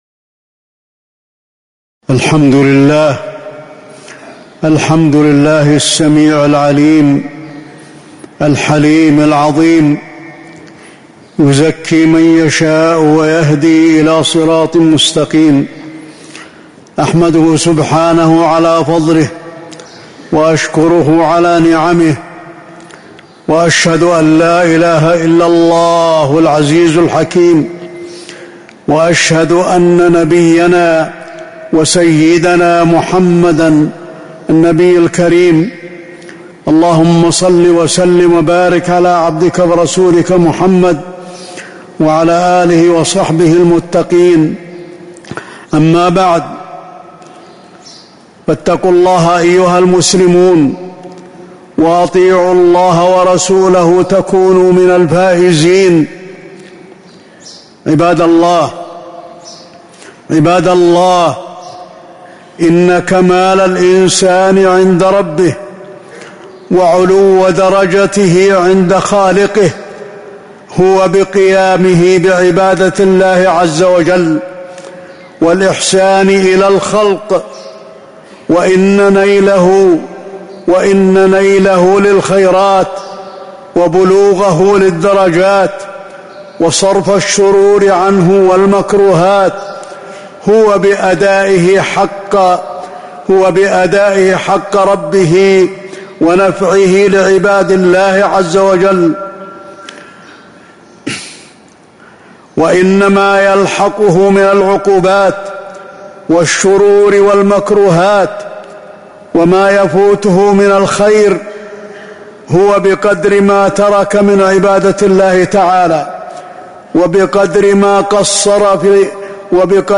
تاريخ النشر ١٤ رمضان ١٤٤٦ هـ المكان: المسجد النبوي الشيخ: فضيلة الشيخ د. علي بن عبدالرحمن الحذيفي فضيلة الشيخ د. علي بن عبدالرحمن الحذيفي العبادة أهميتها وفضائلها